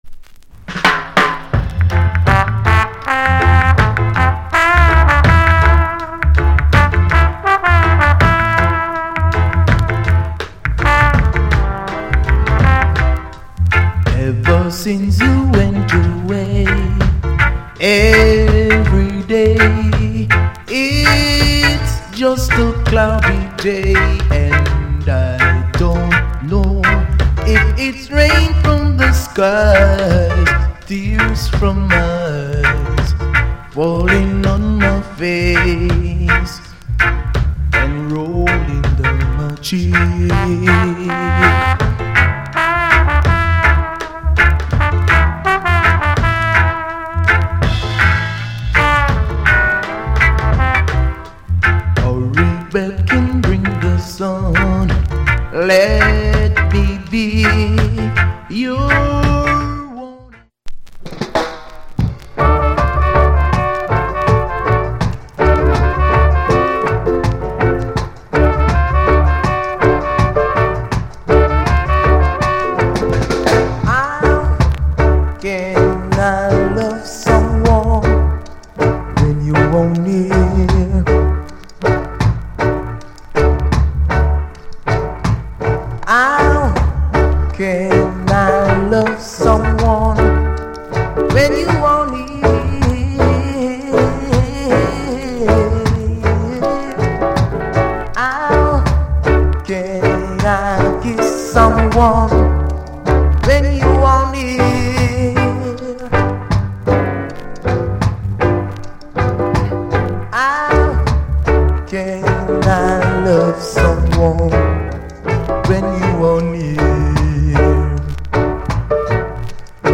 Genre Rock Steady / Male Vocal